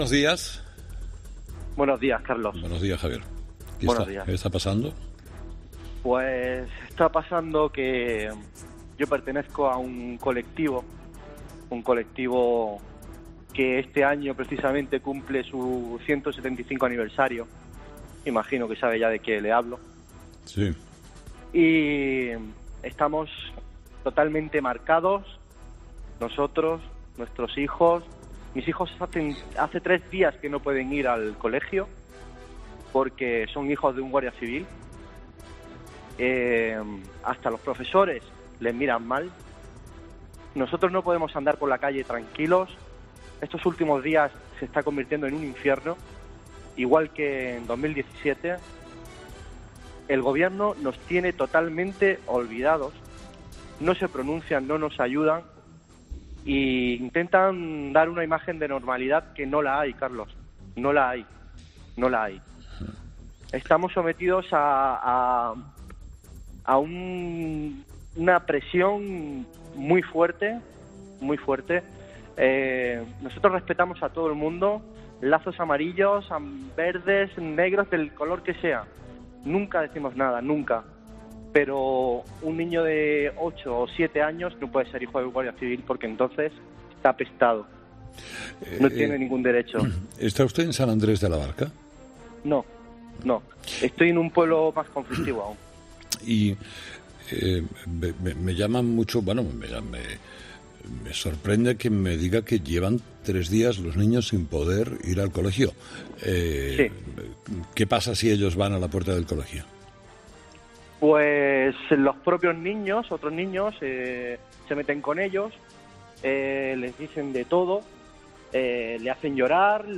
Entrevistado: "Guardia Civil"